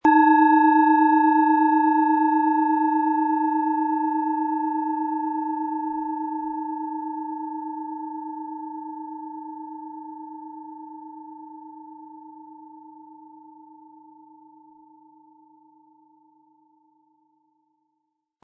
Planetenschale® Sinnlich Sein und Fühlen & Hemmungen verlieren mit Eros, Ø 10,8 cm, 100-180 Gramm inkl. Klöppel
Planetenton 1
Diese tibetanische Eros Planetenschale kommt aus einer kleinen und feinen Manufaktur in Indien.
Um den Originalton der Schale anzuhören, gehen Sie bitte zu unserer Klangaufnahme unter dem Produktbild.
Harmonische Töne erhalten Sie, wenn Sie die Schale mit dem kostenfrei beigelegten Klöppel ganz sanft anspielen.